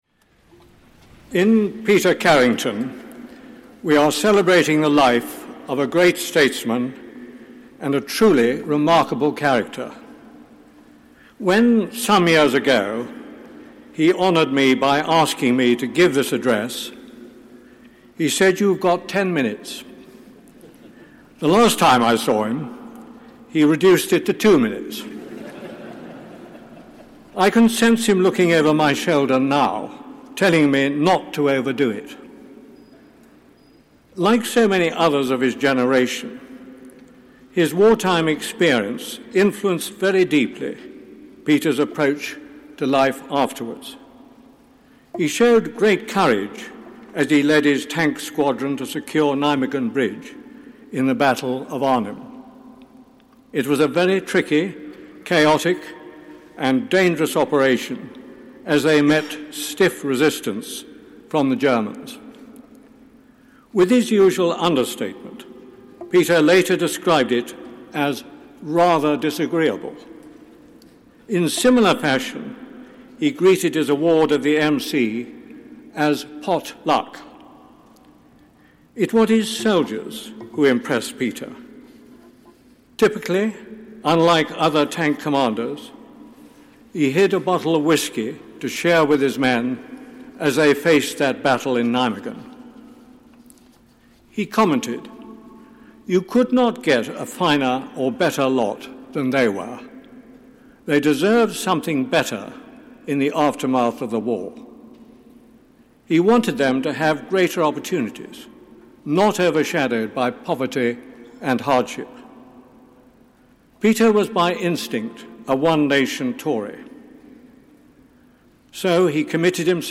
Address given by Lord Luce at the Lord Carrington thanksgiving service
Address given by The Right Honourable the Lord Luce at A Service of thanksgiving for The Right Honourable the Lord Carrington